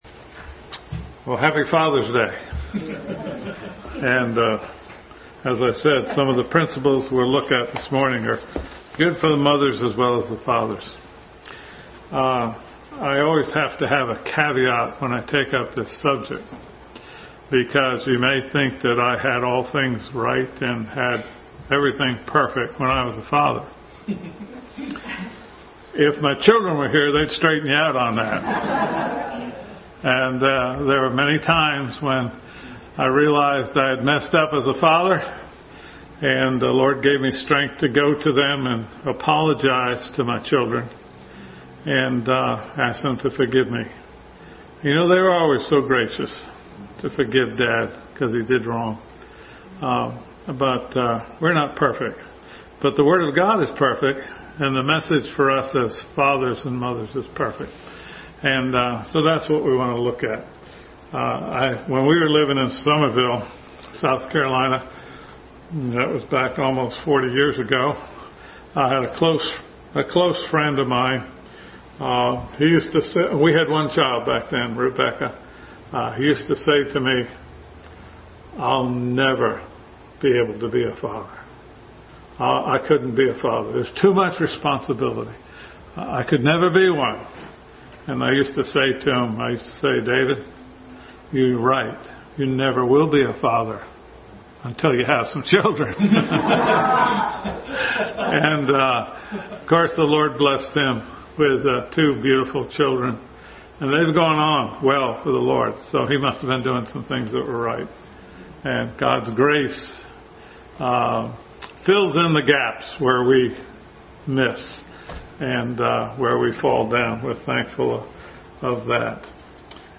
Family Bible Hour Message.
Various Scriptures Service Type: Family Bible Hour Family Bible Hour Message.